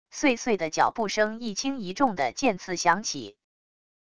碎碎的脚步声一轻一重的渐次响起wav音频